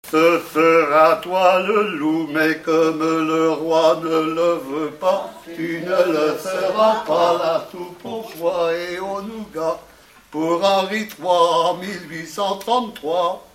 enfantine : lettrée d'école
Enquête Arexcpo en Vendée-C.C. Saint-Fulgent
Pièce musicale inédite